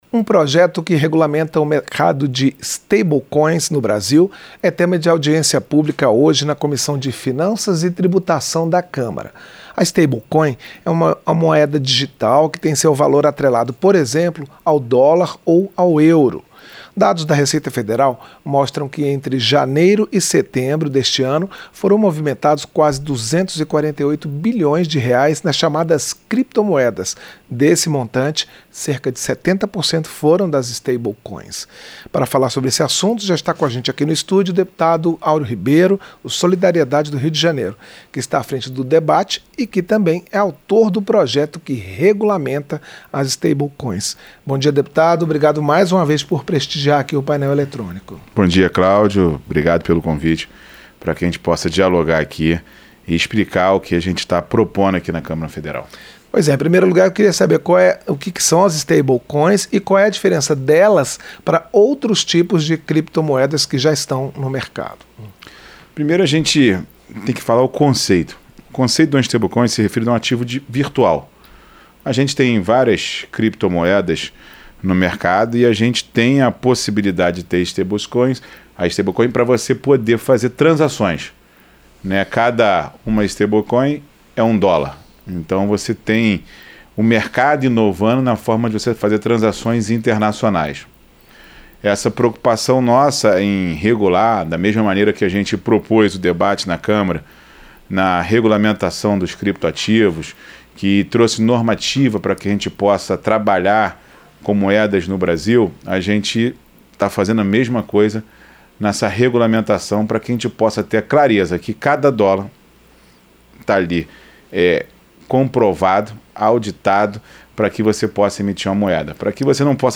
Entrevista - Dep. Aureo Ribeiro (Solidariedade-RJ)